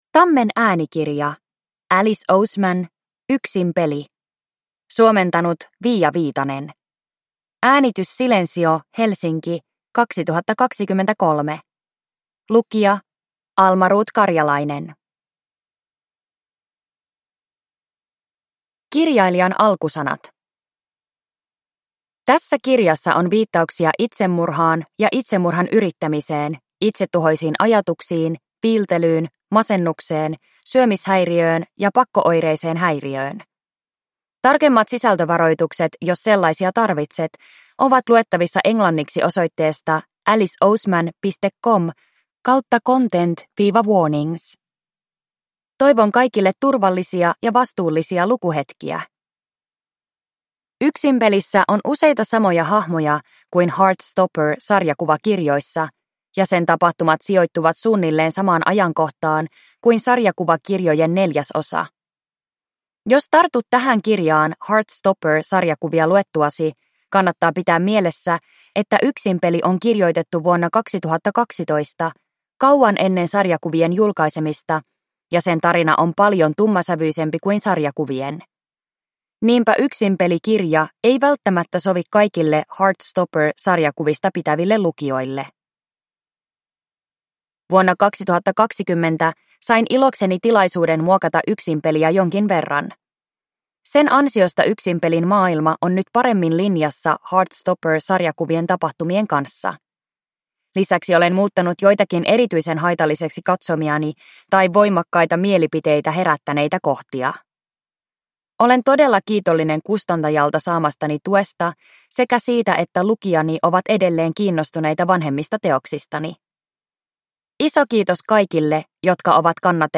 Yksinpeli – Ljudbok